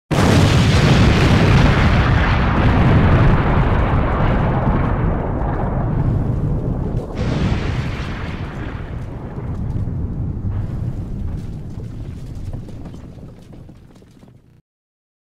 Explosion
Explosion-Sound-Effect.mp3